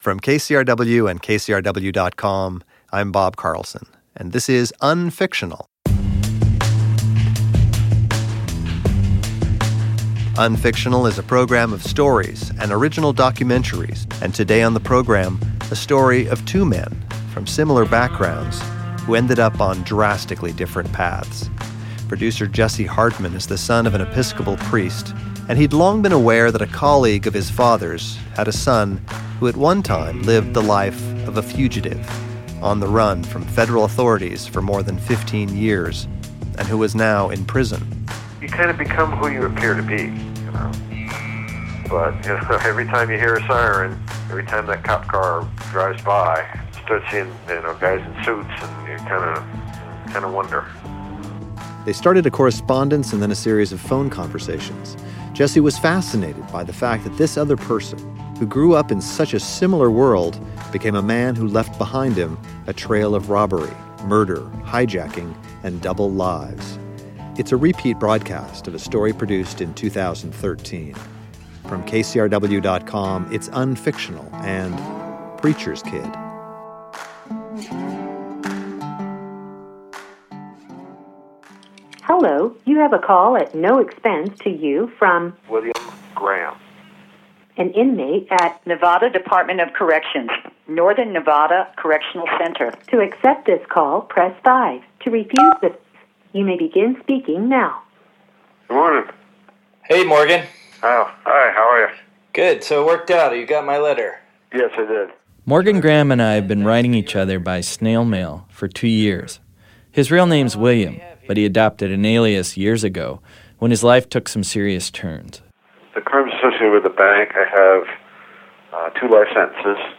and then a series of telephone conversations from prison.